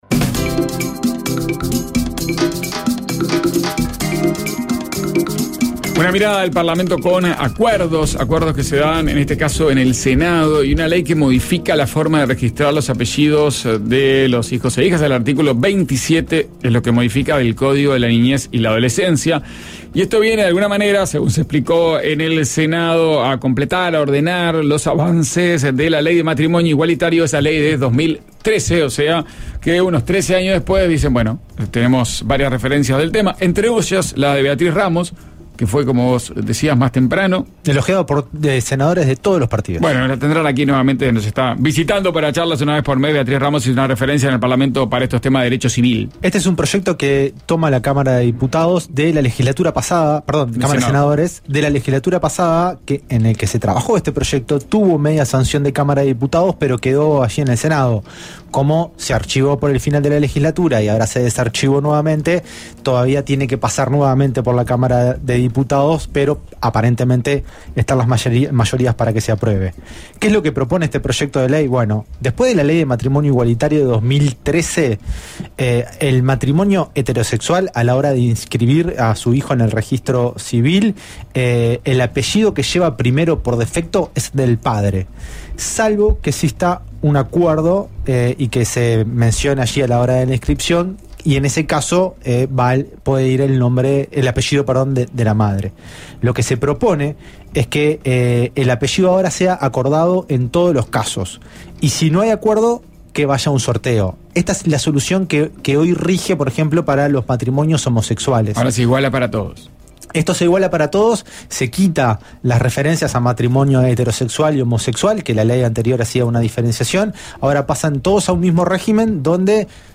Informe a propósito de la “banca de bronce”. Cómo Blanca Rodríguez o Mario Carrero pueden llegar a la Presidencia, según la interpretación que se haga de la línea de sucesión en el Senado, a partir de la salida de Pacha Sánchez hacia el Ejecutivo.